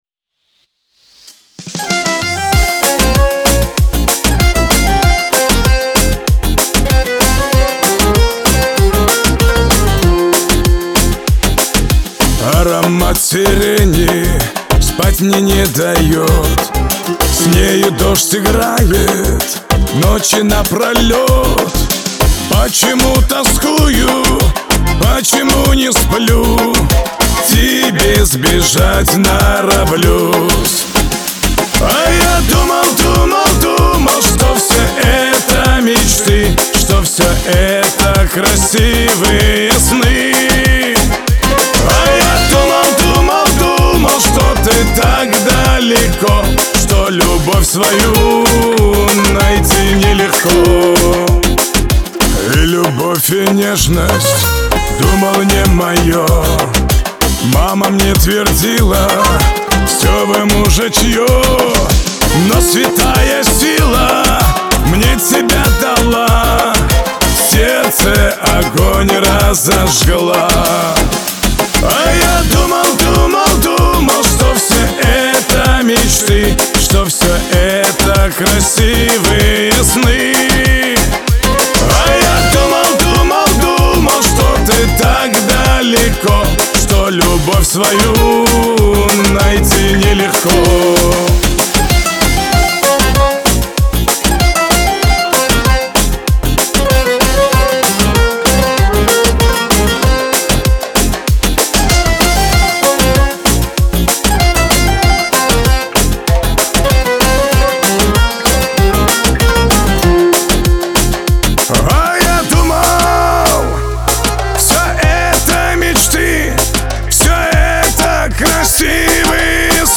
Лирика , Кавказ – поп